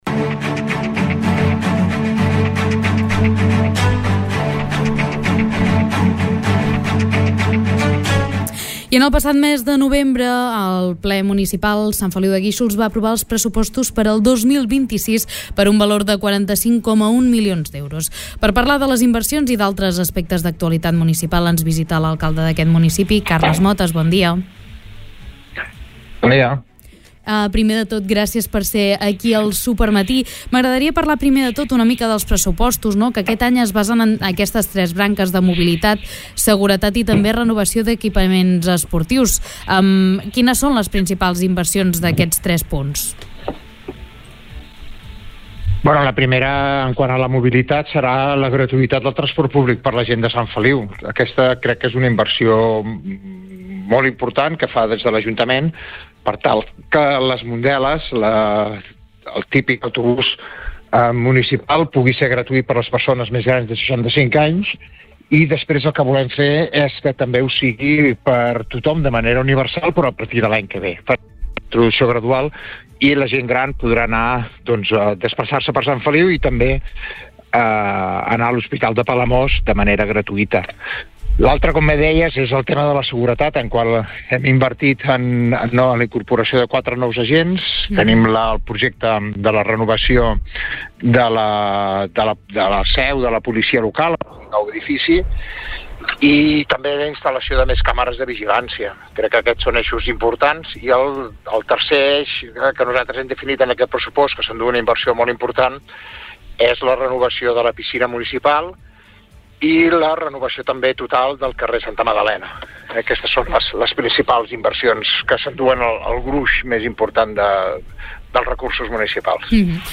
En una entrevista concedida al programa Supermatí, l’alcalde de Sant Feliu de Guíxols, Carles Motas, ha confirmat que té la voluntat de tornar-se a presentar a les eleccions municipals de 2027.